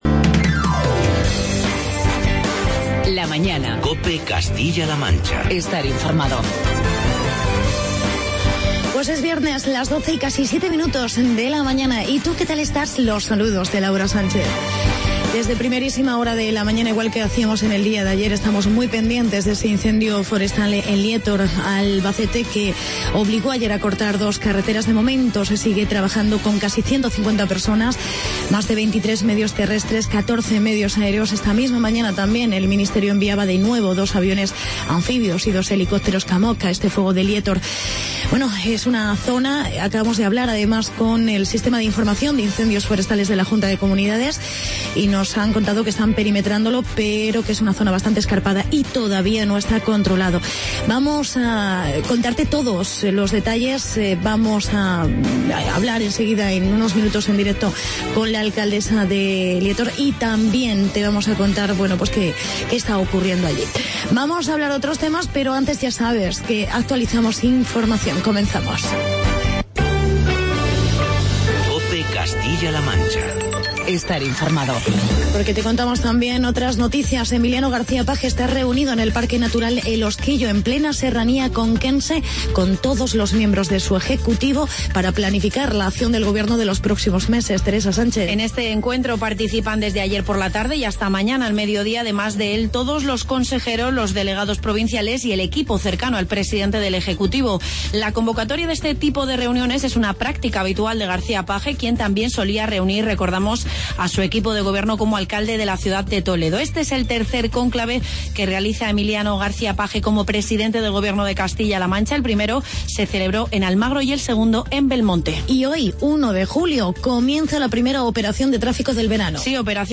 Entrevista con la alcaldesa de Liétor, Josefa Moreno por el incendio en el Paraje de Las Quebradas.